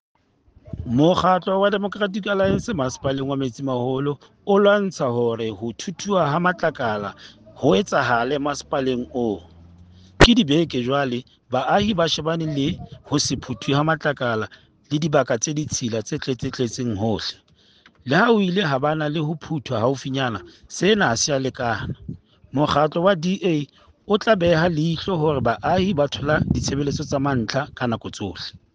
Sesotho soundbite by Cllr Stone Makhema.